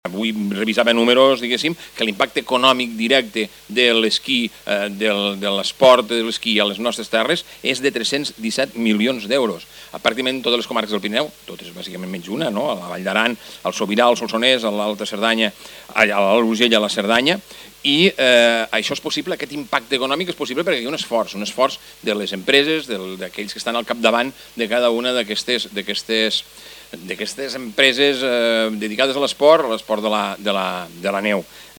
El president de la Diputació de Lleida, Joan Talarn, parla de l'impacte econòmic de les estacions del Pirineu.